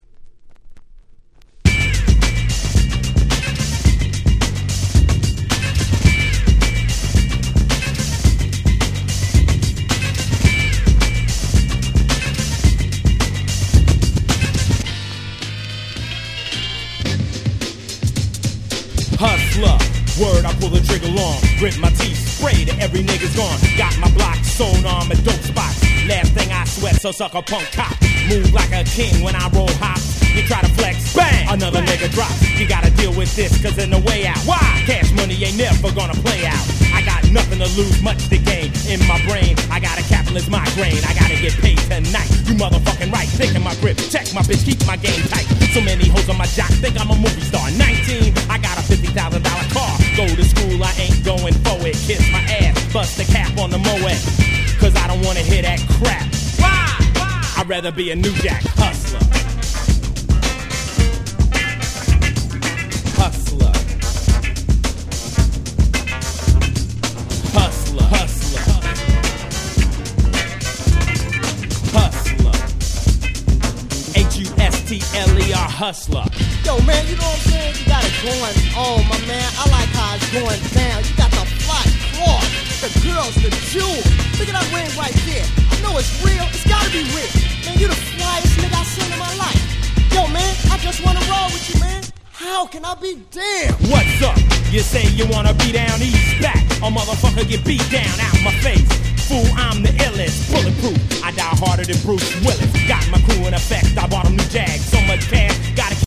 91' Smash Hit Hip Hop / New Jack Swing !!
FunkyなBeatにOld School仕込みのFlowが超絶格好良い鉄板の1曲！！
90's Boom Bap ブーンバップ